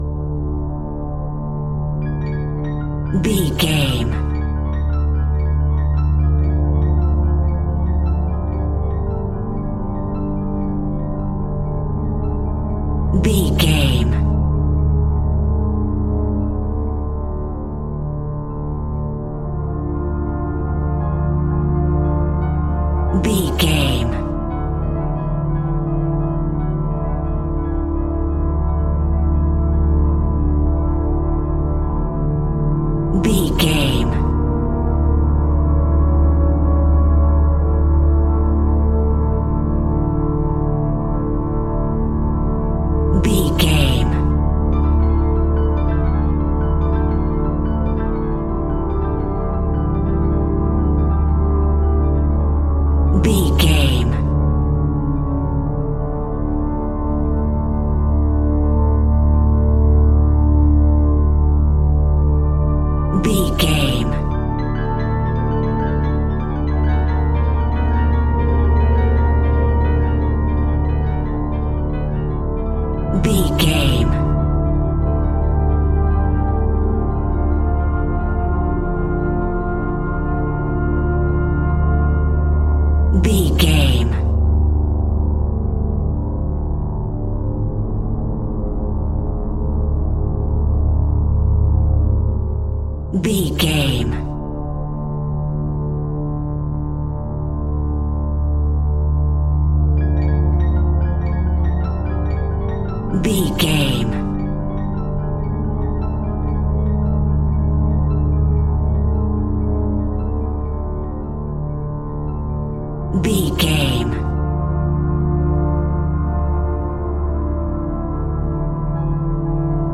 John Carpenter Style Music.
Augmented
D
ominous
haunting
eerie
piano
synthesiser
strings
dark atmospheres
Synth Pads
Synth Ambience